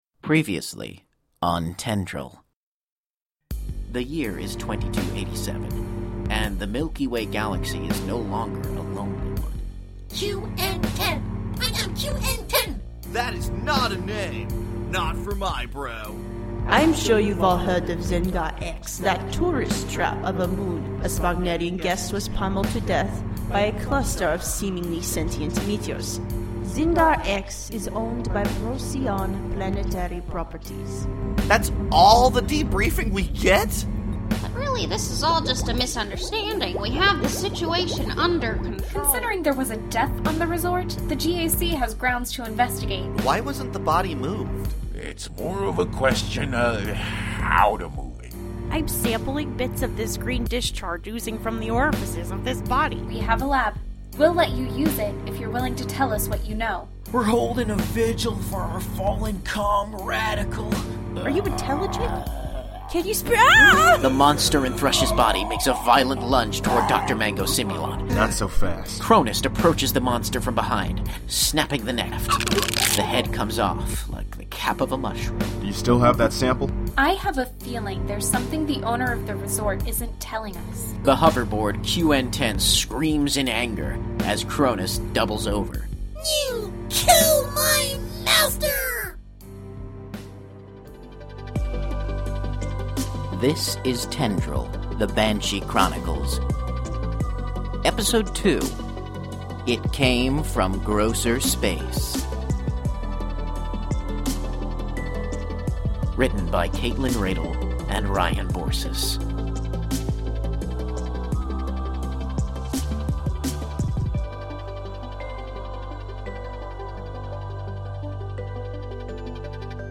We want to give a huge thanks our patreon supporters, listeners, cast, friends, and family for their continued support and dedication to helping us make this audio drama podcast a reality.